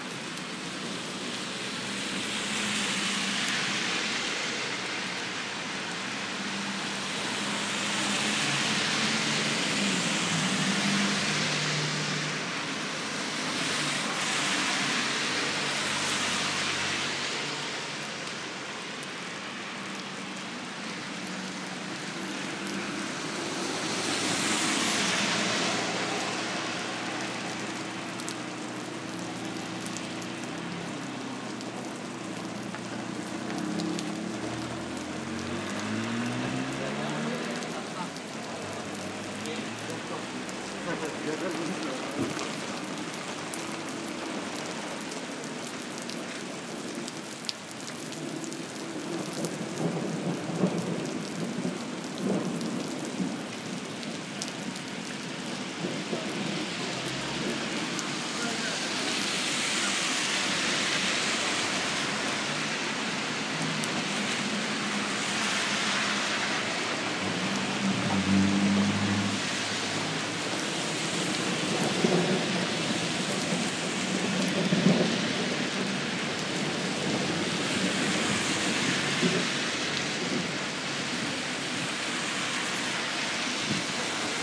Urban rumble — cars, planes, and thunder